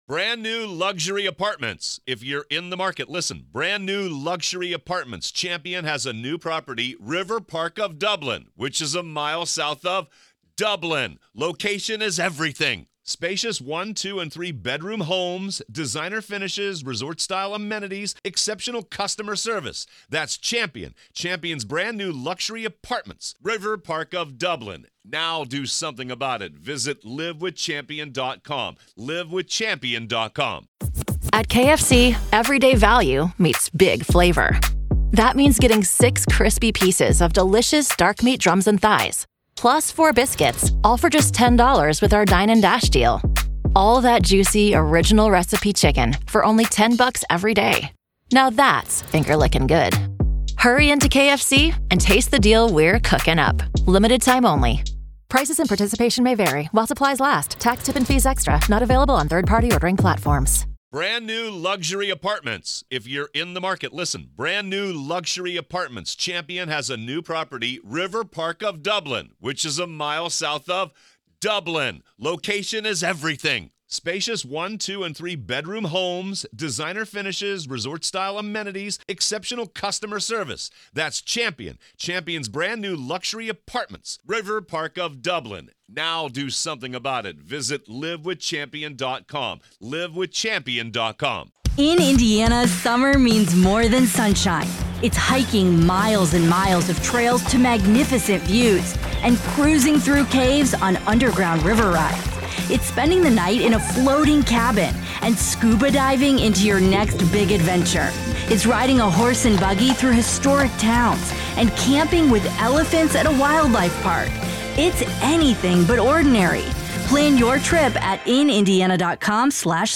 In Part 2 of our conversation